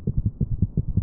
Katze: Gallop Rhythmus- weiteres Beispiel S3 aufgrund von HCM (hypertropher Kardiomyopathie
CatGallop2.wav